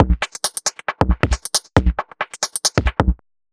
Index of /musicradar/uk-garage-samples/136bpm Lines n Loops/Beats
GA_BeatFilterB136-05.wav